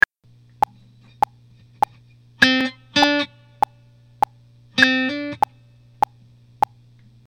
Hammer-ons and Pull-offs  produce a legato sound on a single string.
The only distinction between the two techniques is that hammer-ons slur to a higher pitch while pull-offs slur to a lower pitch.
3. Without releasing the first fretted note, quickly hammer or push down the second note without plucking the string again.
Hammer-on
hammeron.mp3